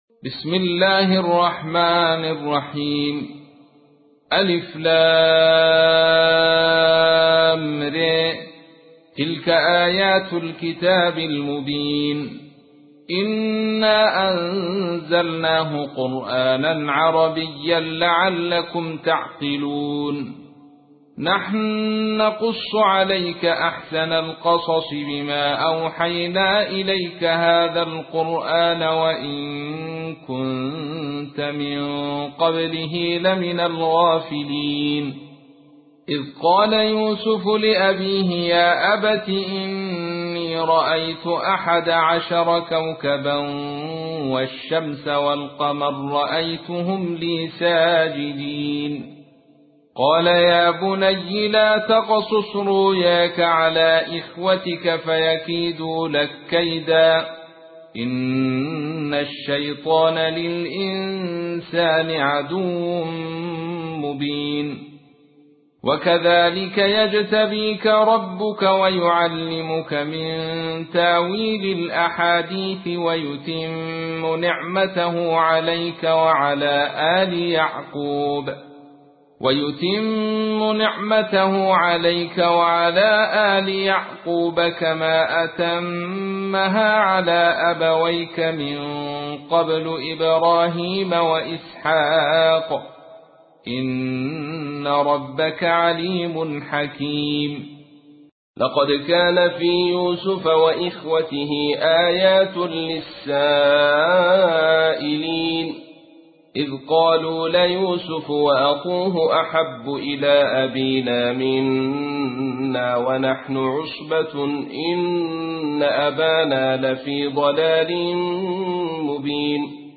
تحميل : 12. سورة يوسف / القارئ عبد الرشيد صوفي / القرآن الكريم / موقع يا حسين